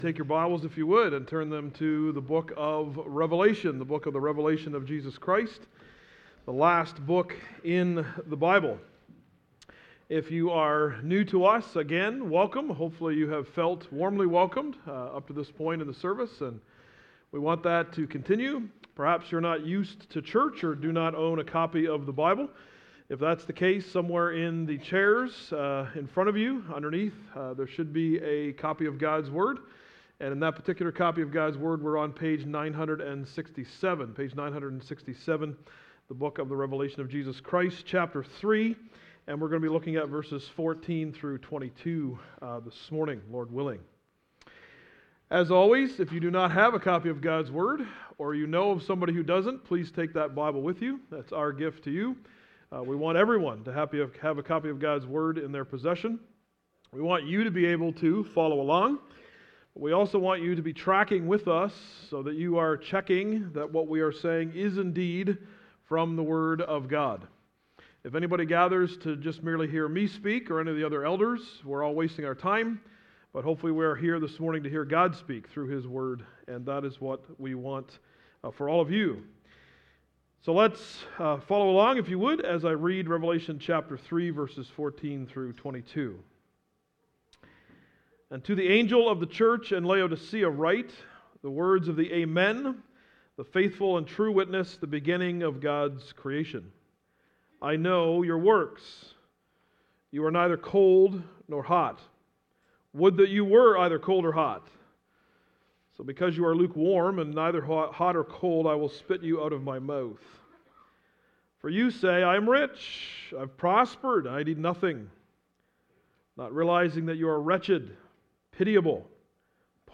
Blinded Grace Sermon podcast